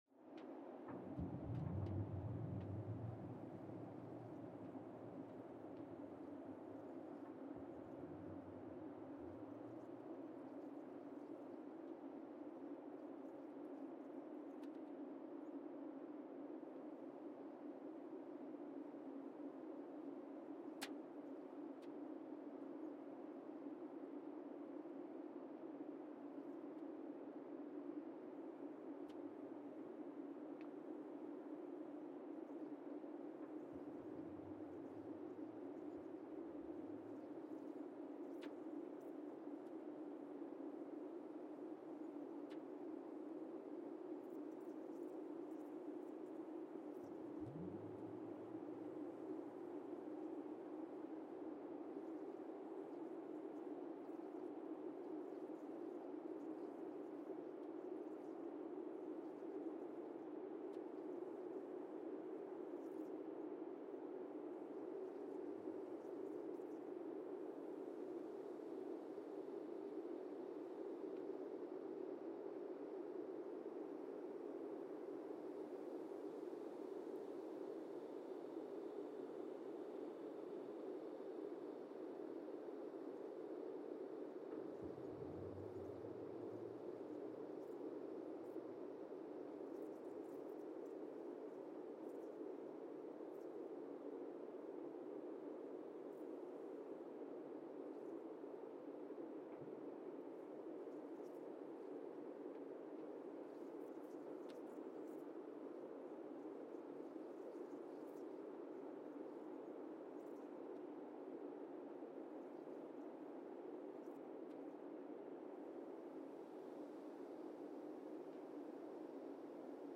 Monasavu, Fiji (seismic) archived on December 15, 2024
Sensor : Teledyne Geotech KS-54000 borehole 3 component system
Speedup : ×1,800 (transposed up about 11 octaves)
SoX post-processing : highpass -2 90 highpass -2 90